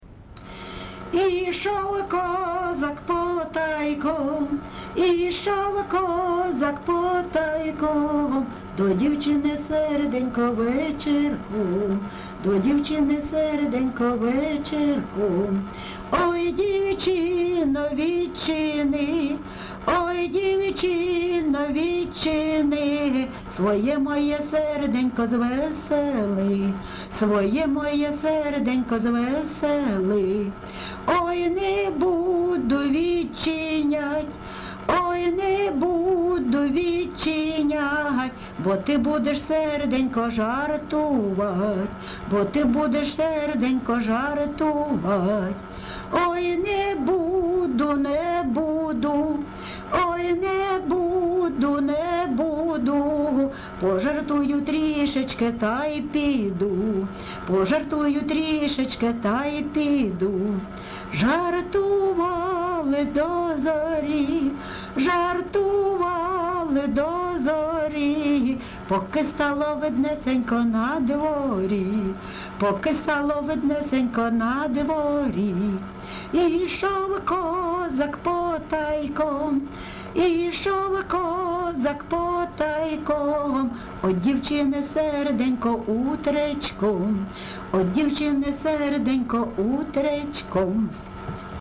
ЖанрПісні з особистого та родинного життя
Місце записус. Лозовівка, Старобільський район, Луганська обл., Україна, Слобожанщина